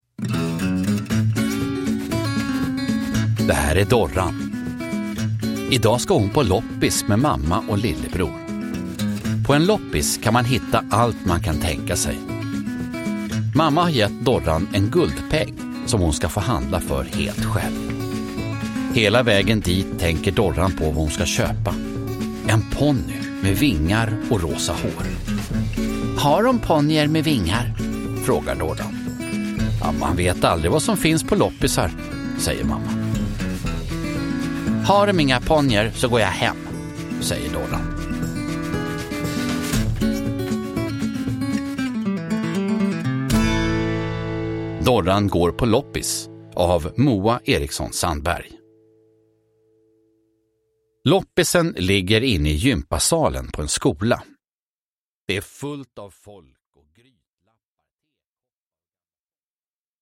Dorran går på loppis – Ljudbok – Laddas ner